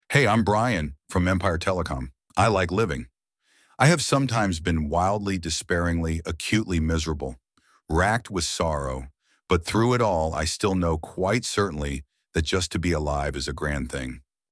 Empire Telecom offers its customers free, professionally recorded auto-attendant greetings and voicemail messages.
Male